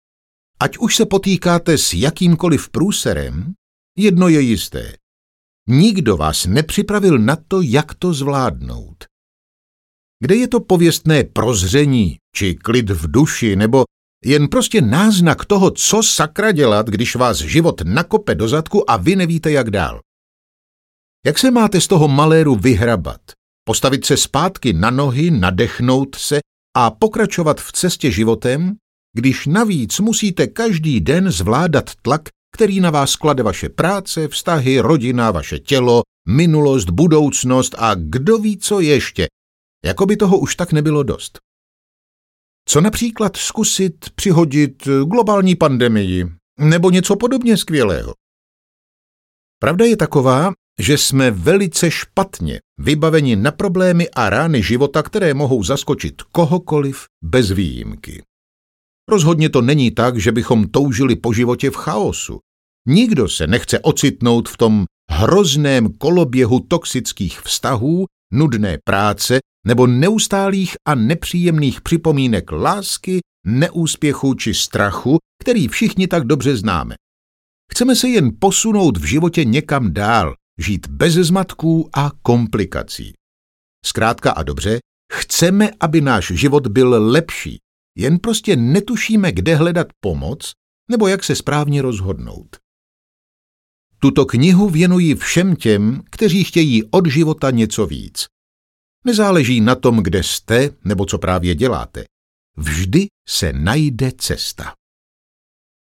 Ukázka z knihy
chytre-na-pru-ery-audiokniha